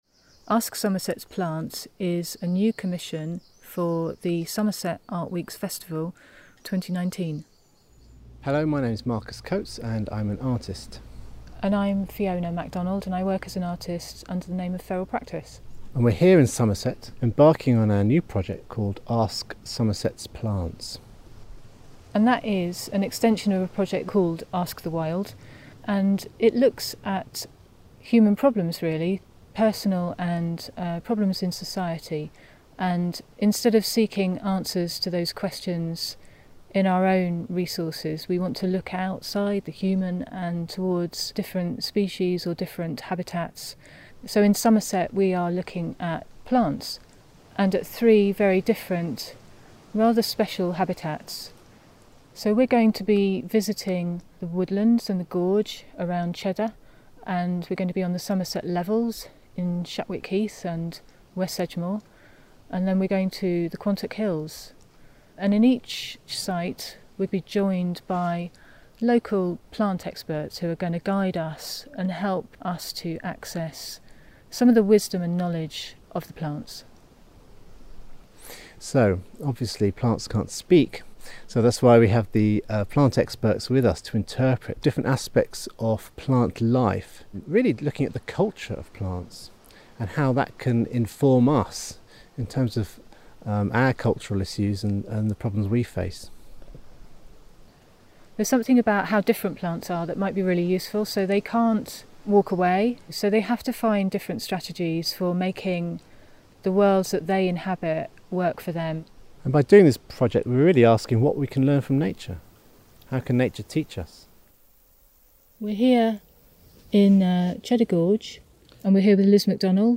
Recording took place in Kings Wood and in various locations in Cheddar Gorge.